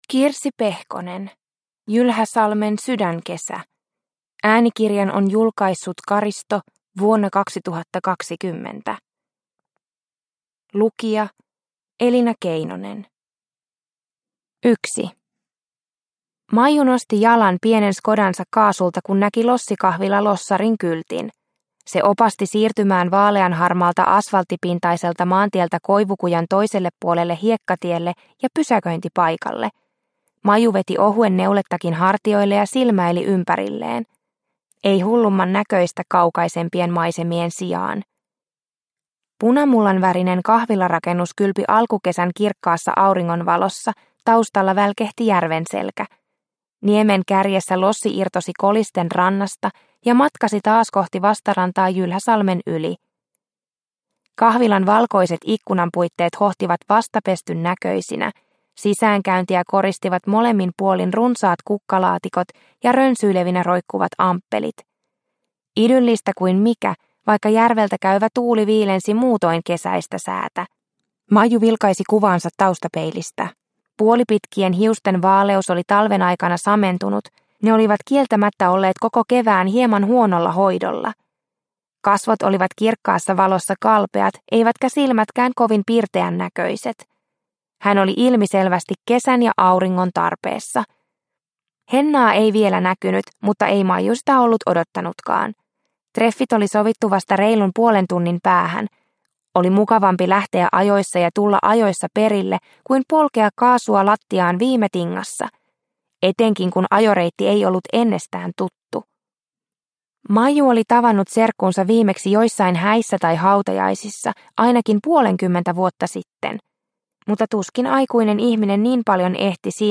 Jylhäsalmen sydänkesä – Ljudbok – Laddas ner